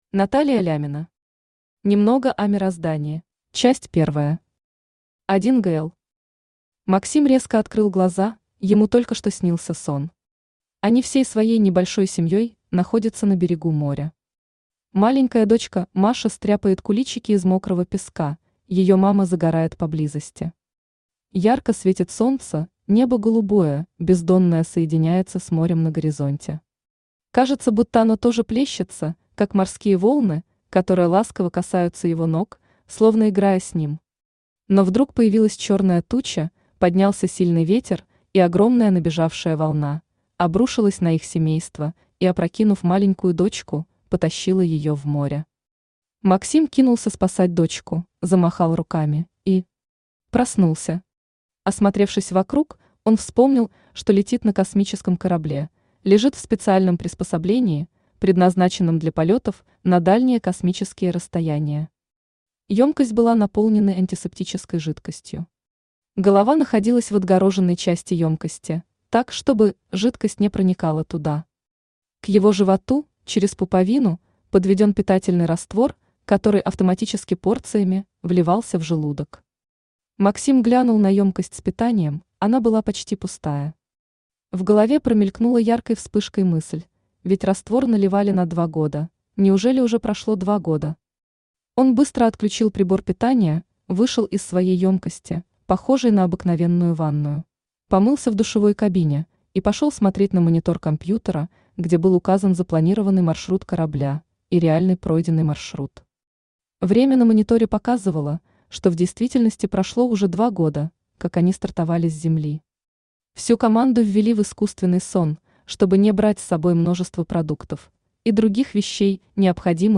Аудиокнига Немного о мироздании | Библиотека аудиокниг